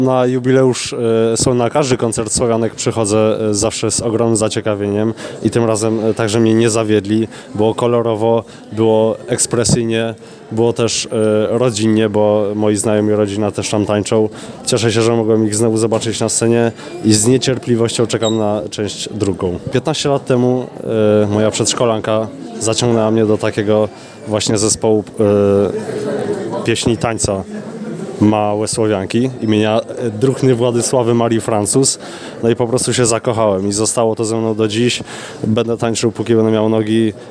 Tak skomentował pierwszy z koncertów jeden z widzów: